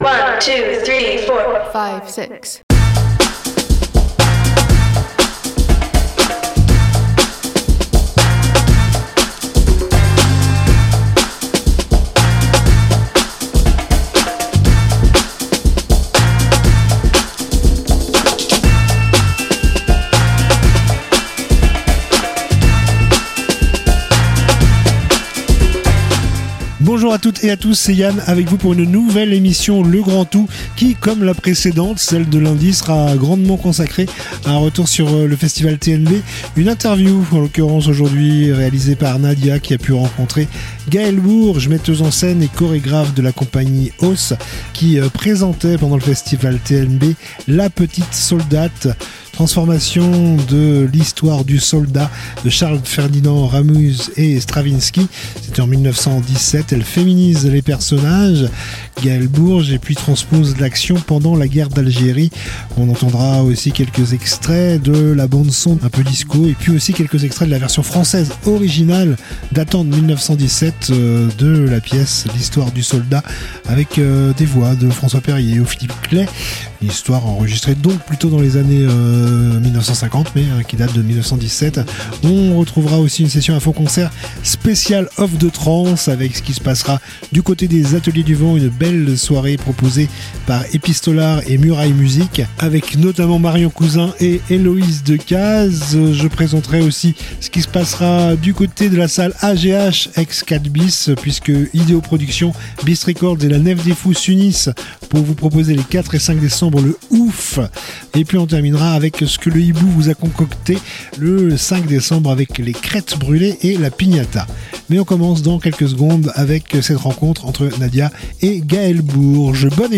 Retours sur le Festival TNB, part 02/02 : Itv